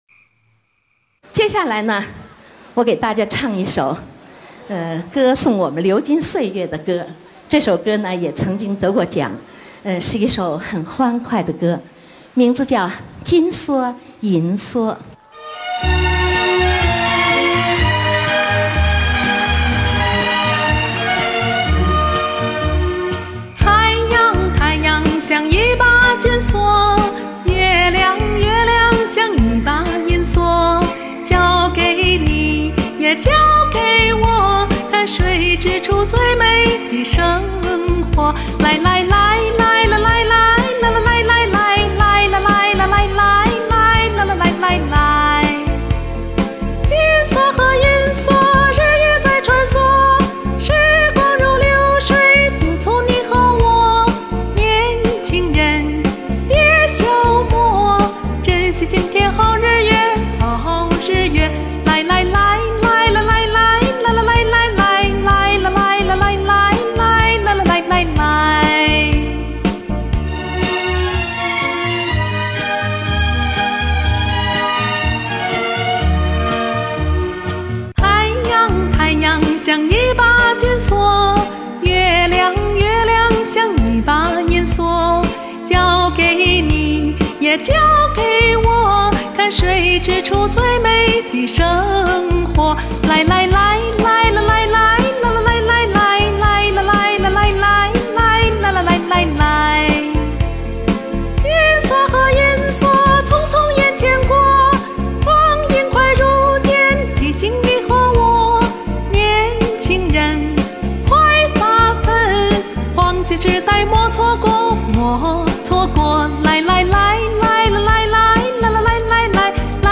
具有浓郁的时代气息。
欢快的歌声，唤起青春的美好回忆！
一股清爽、向上的风扑面而来👍